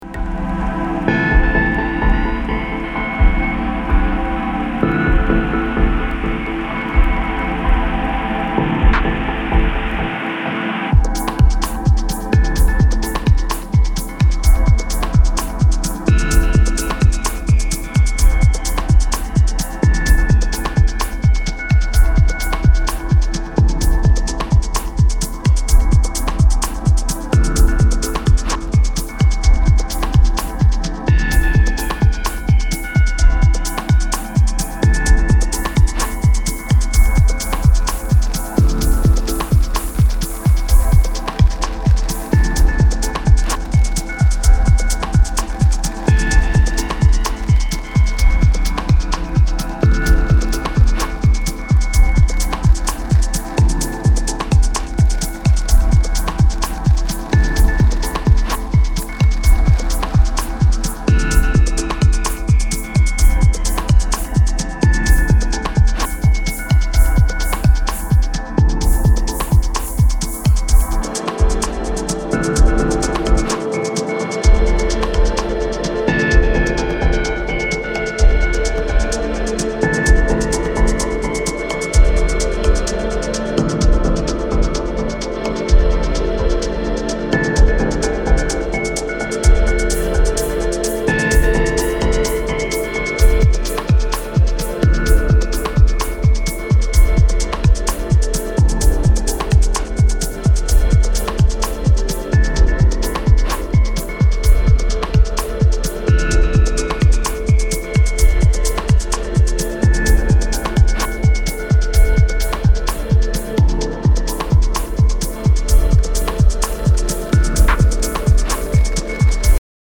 ジェントルにディレイを効かせたパッドと閃光の如きシャッフルショットが延々と忘我と覚醒を反復させる
ダブ・テクノの新しい潮流を感じさせる一枚です。